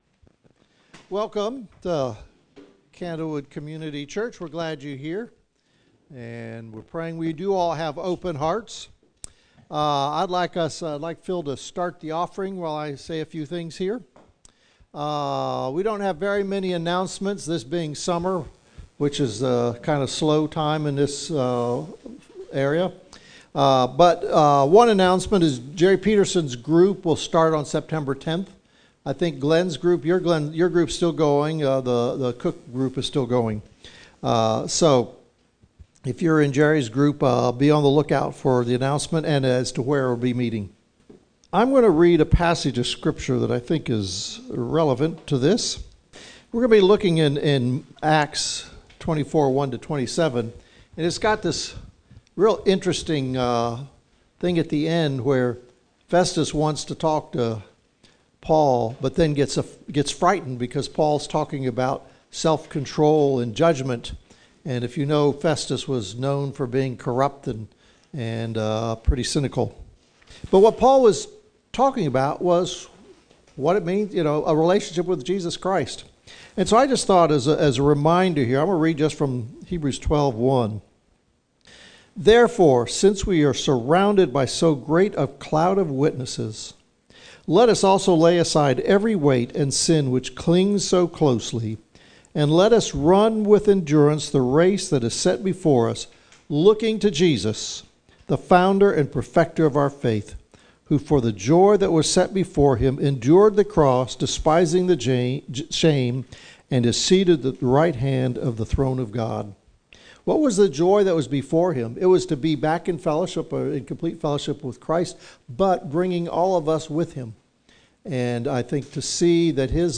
Acts 24 Service Type: Gathering Once again